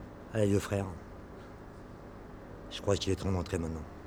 Voix et ambiance
sans_electro.wav